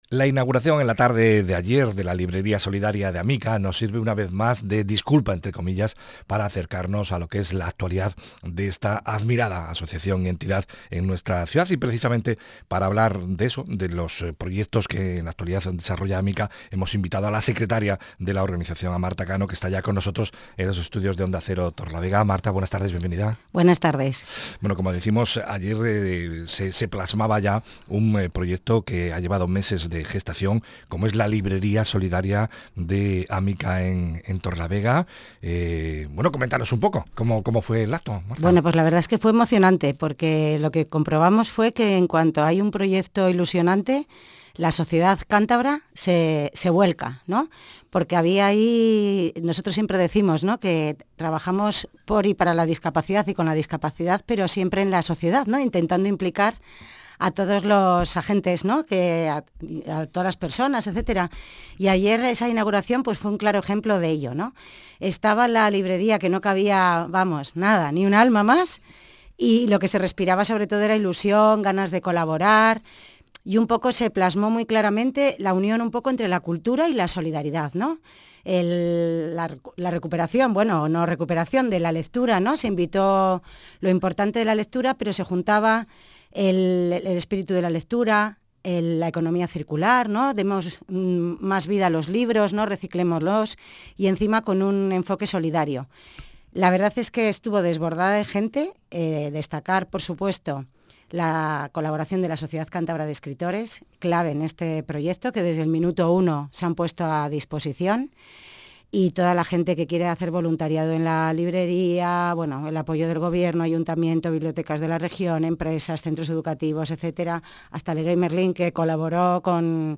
Entrevista en Onda Cero: “Proyectos de Amica”